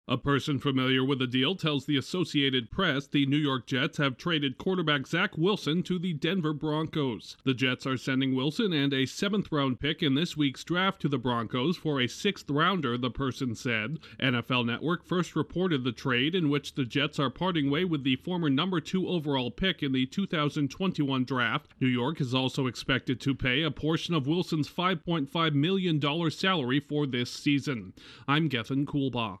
The Jets are moving on from a quarterback they drafted second-overall three years ago. Correspondent